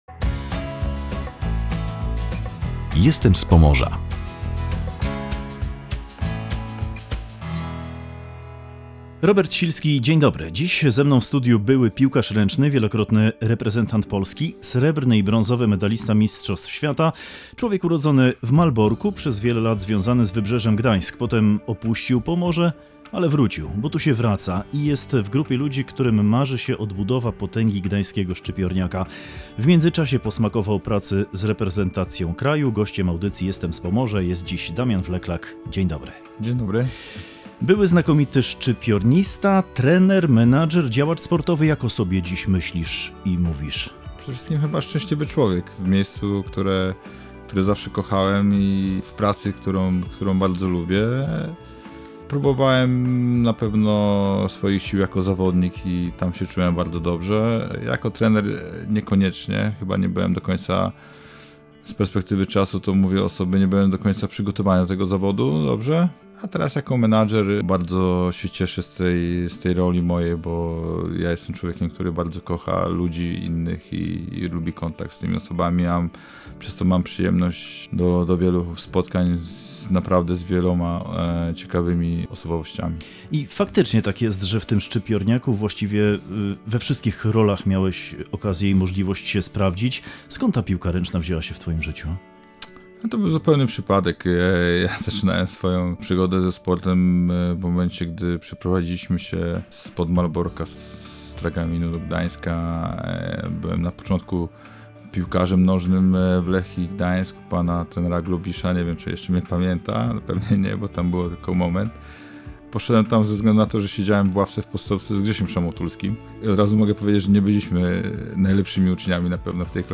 Rozmowa z Damianem Wleklakiem